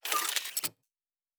pgs/Assets/Audio/Sci-Fi Sounds/Weapons/Weapon 08 Reload 3 (Laser).wav at master
Weapon 08 Reload 3 (Laser).wav